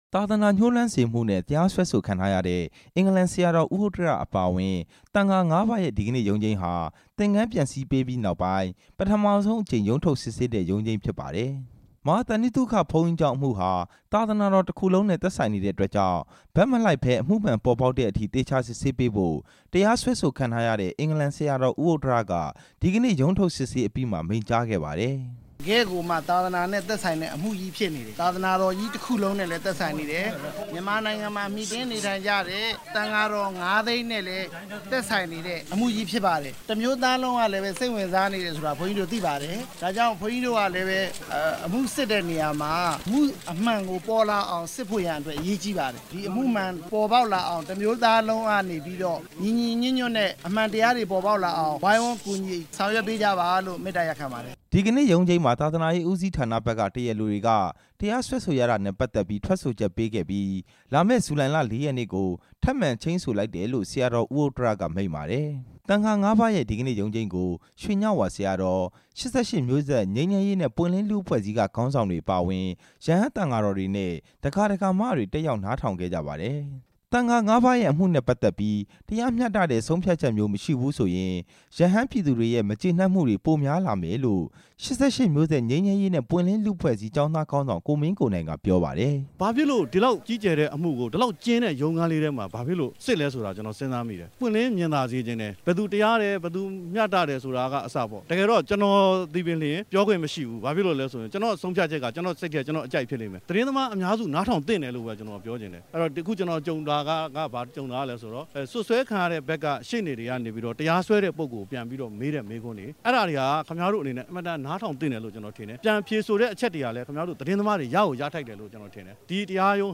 ရန်ကုန်တိုင်း တာမွေမြို့နယ် မဟာသန္တိသုခကျောင်း တိုက်က သံဃာ ၅ ပါးအပေါ် စွဲချက်တင်ထားတဲ့အမှု အပေါ် တရားရုံးကစီရင်ချက်ဟာ မြန်မာ့နိုင်ငံရေး အပေါ်မှာ အကျိုးသက်ရောက်မှုတွေ ရှိနိုင်တယ်လို့ ၈၈ မျိုးဆက်ကျောင်းသားခေါင်းဆောင် ကိုမင်းကိုနိုင်က ပြော လိုက်ပါတယ်။